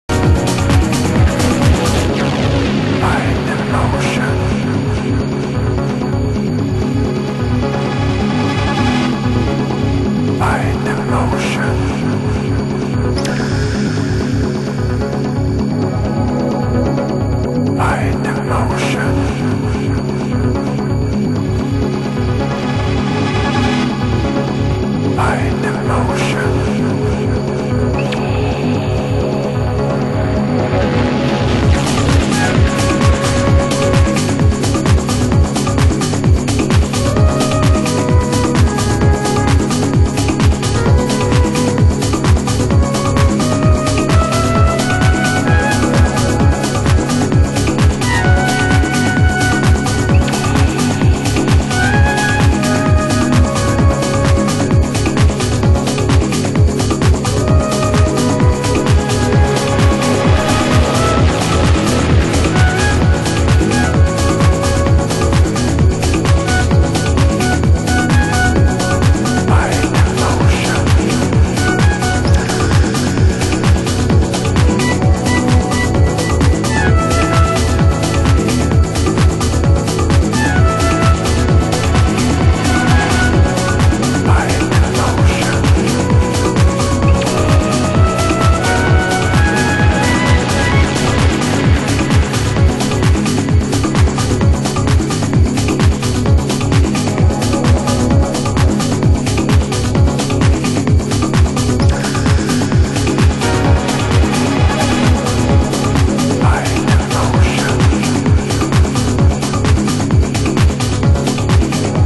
中盤 　　盤質：少しチリパチノイズ有　　ジャケ：全体的にかなり消耗